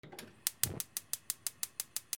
ガスコンロ点火